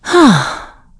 Isolet-Vox-Sigh.wav